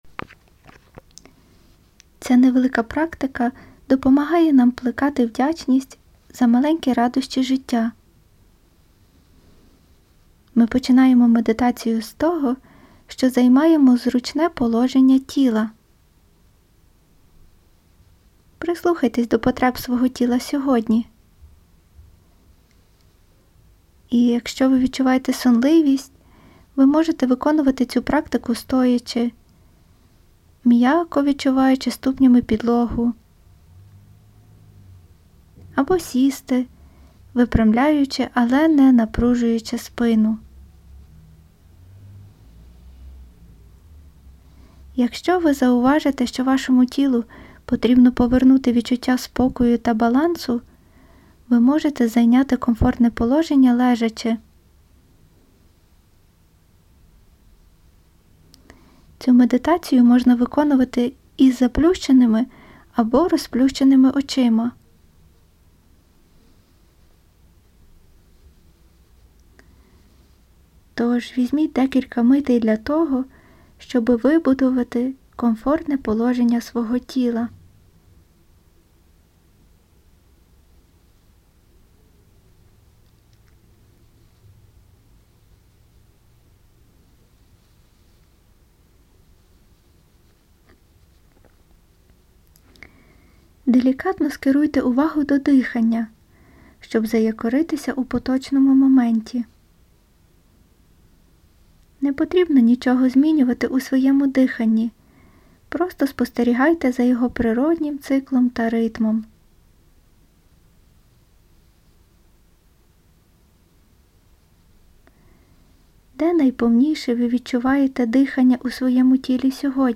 15. Медитація вдячності
Медитація-вдячності.mp3